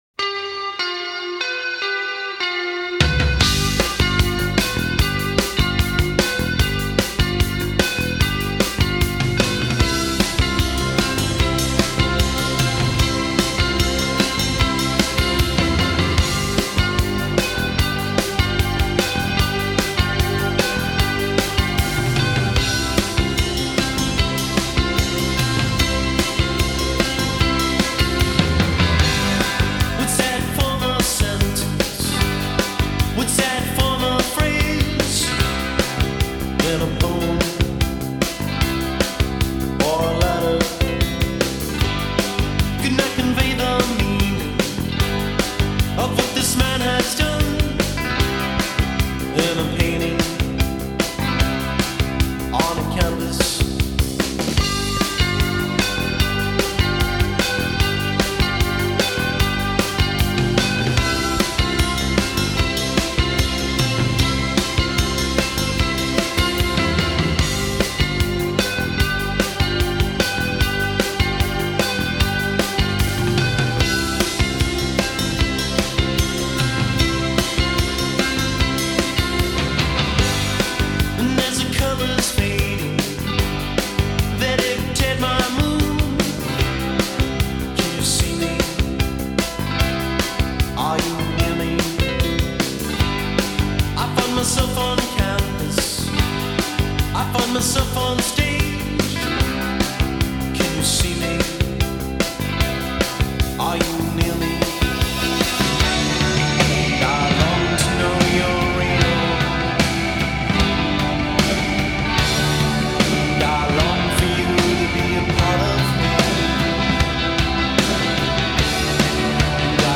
a superb alternative pop band from Pennsylvania.